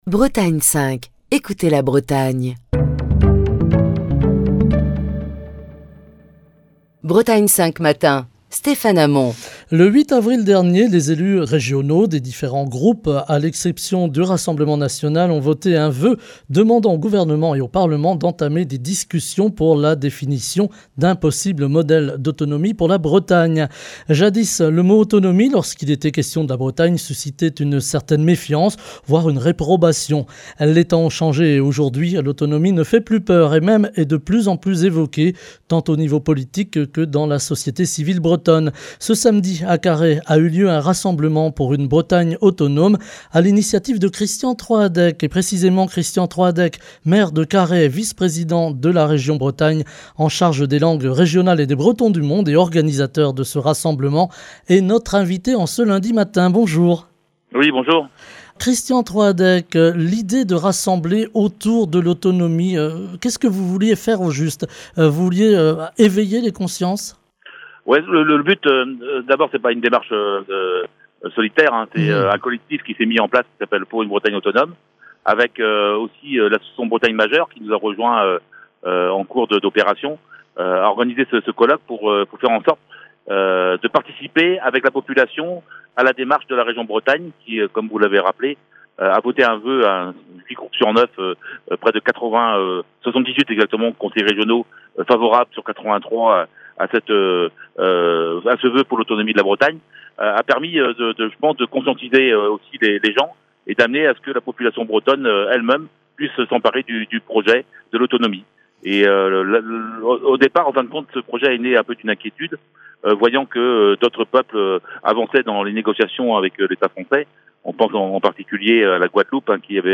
Les temps ont changé et aujourd'hui, l'autonomie ne fait plus peur, et même est de plus en plus évoquée, tant au niveau politique, que dans la société civile bretonne. Ce samedi, à Carhaix a eu lieu un rassemblement pour une Bretagne autonome à l'initiative de Christian Troadec. Christian Troadec, maire de Carhaix et vice-président de la région Bretagne, en charge des langues régionales et des bretons du Monde, et organisateur de ce rassemblement est notre invité ce matin.